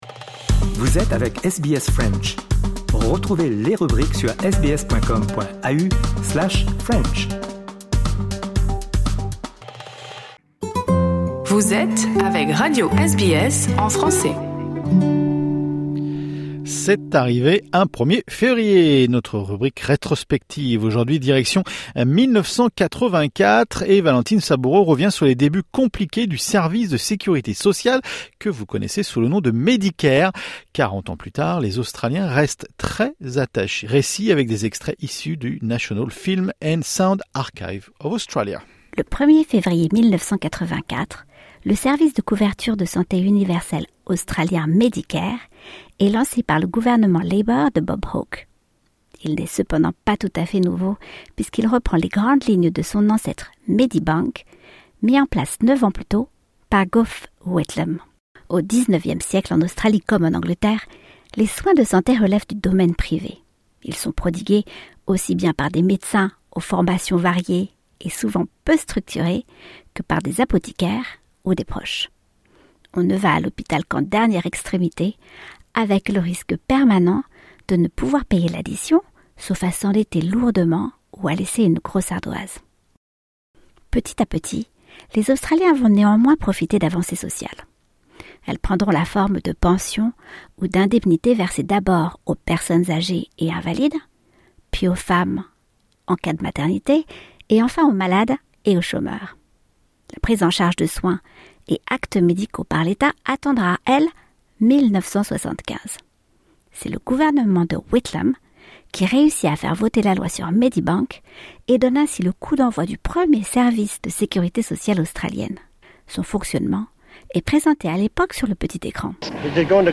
Récit avec des extraits issus de National Film and Sound Archive of Australia.